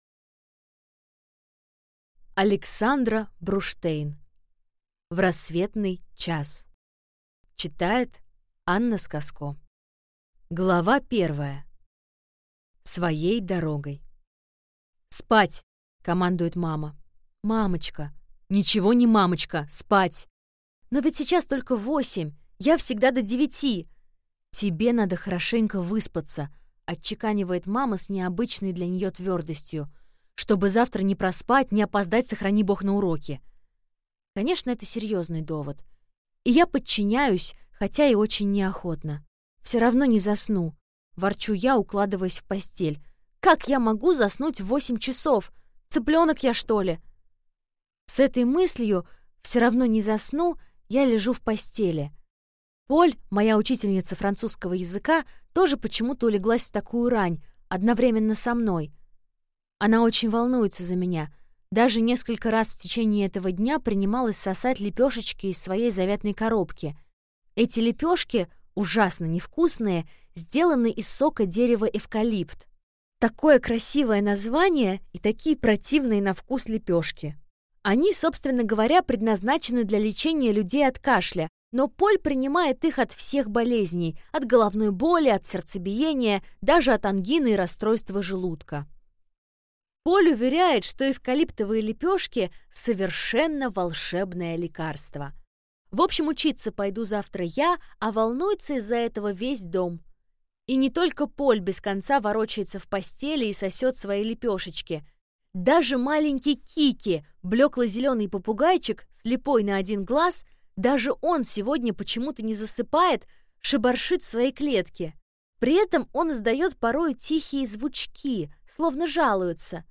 Аудиокнига В рассветный час | Библиотека аудиокниг